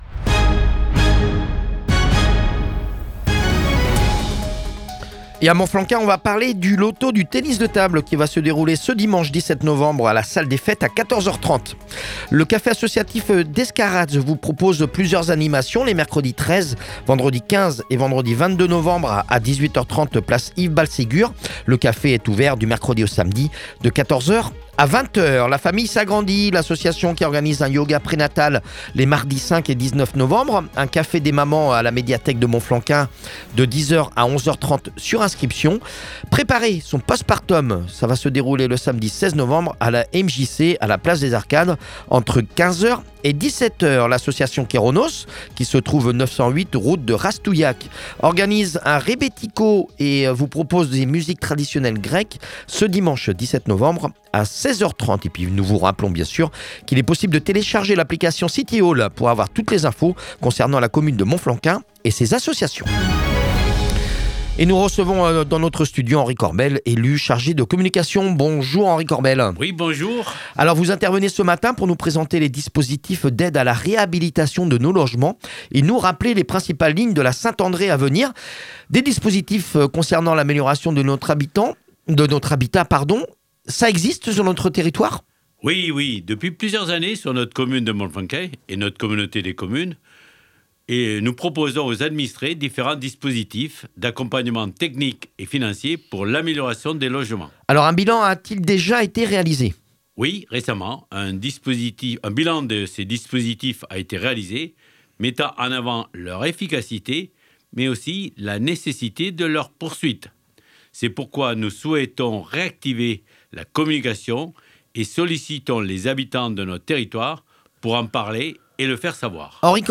VOS ÉLU(E)S  à la RADIO